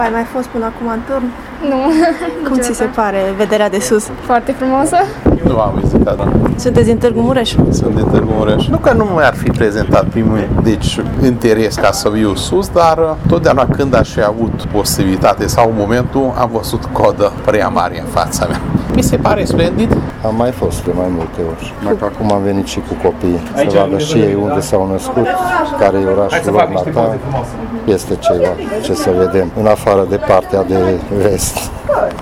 Mulți dintre cei care au privit astăzi Tîrgu-Mureșul de sus au recunoscut că nu au mai făcut acest lucru, fiind un bun prilej de a afla mai multe despre orașul în care trăiesc: